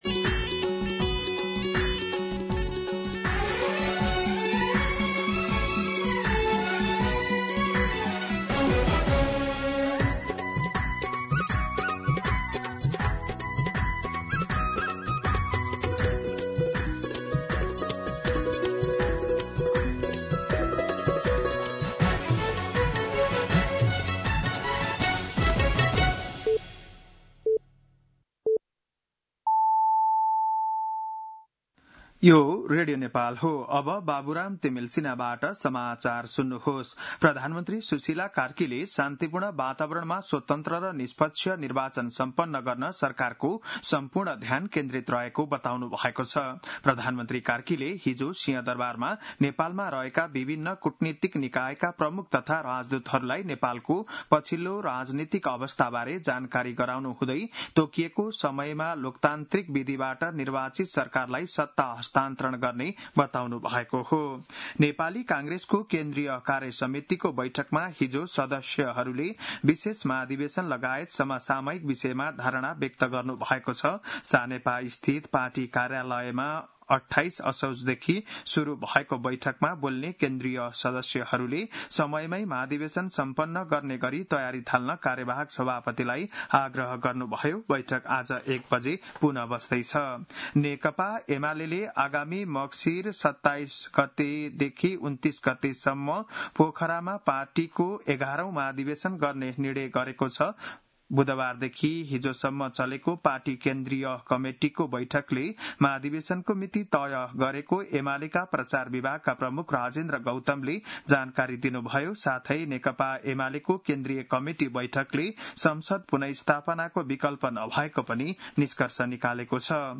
An online outlet of Nepal's national radio broadcaster
बिहान ११ बजेको नेपाली समाचार : १ कार्तिक , २०८२
11am-Nepali-News.mp3